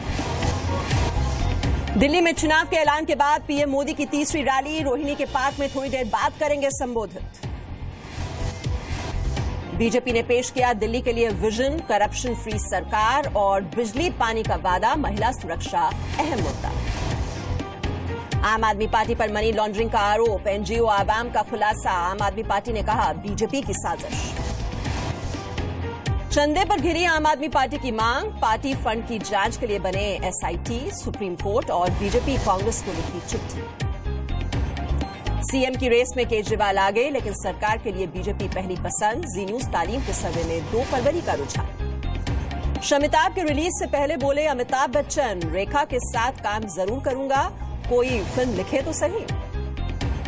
Headlines of the day
Listen to top headlines of the day only on Zee News